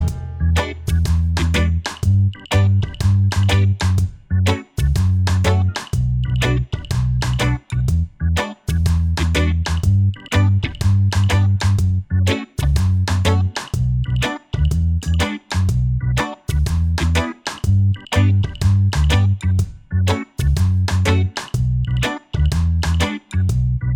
Minus Solo Guitar And Organ Reggae 3:31 Buy £1.50